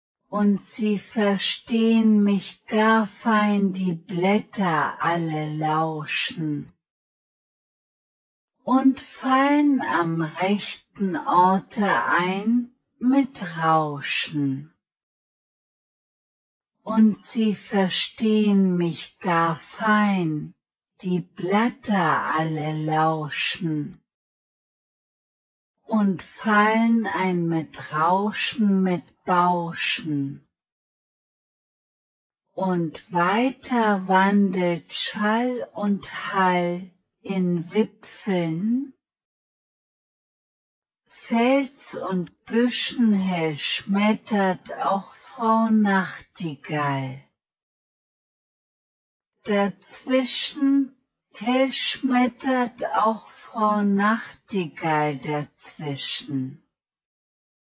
voix femme(22-51)
im-wald-prononce-fille-22-51.mp3